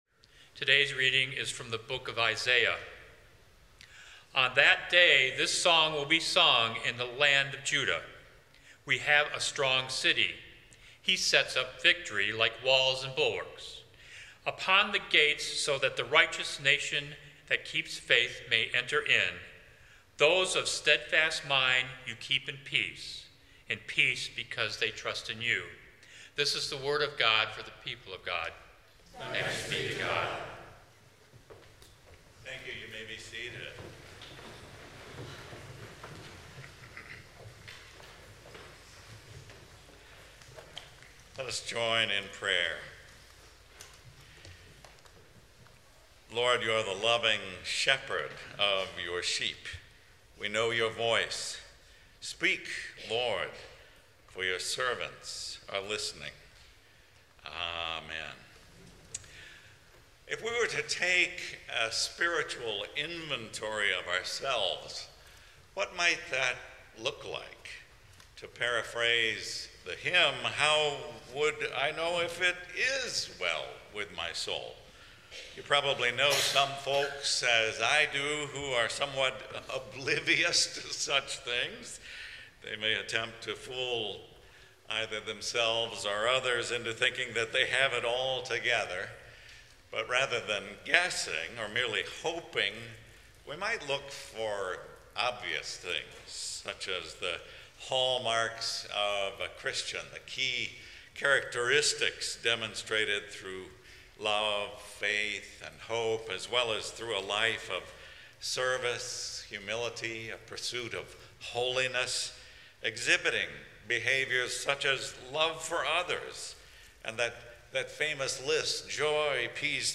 Learn about the hallmarks of Christian character — love, faith, hope, service, humility, and holiness — and how to practice a selfless love that embodies Wesley's call to "do all the good you can." Sermon Reflections: What would a "spiritual inventory" of your soul reveal right now?